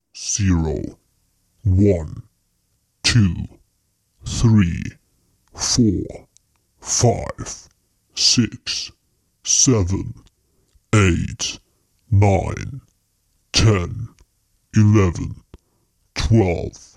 数数的声音包 " 数数的深沉史诗般的声音 012
描述：一个深沉的声音从零到十二。
声道立体声